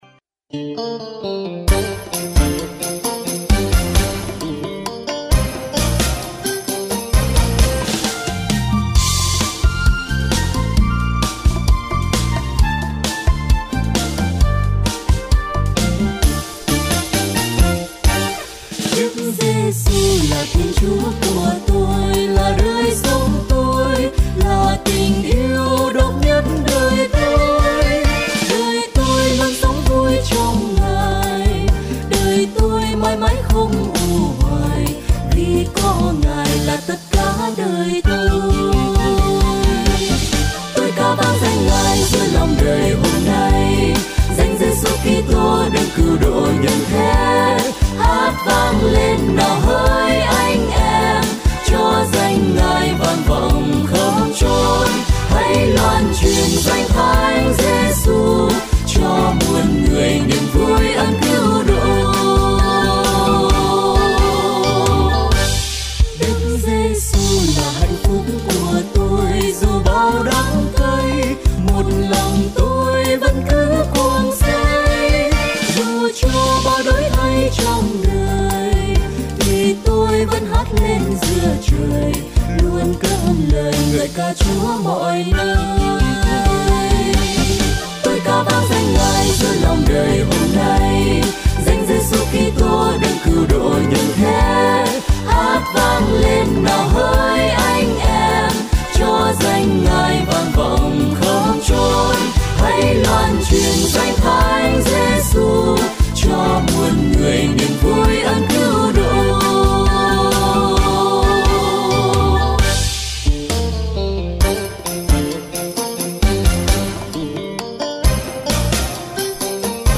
Thể hiện bởi: Tốp ca